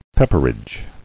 [pep·per·idge]